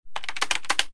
keyboard2.ogg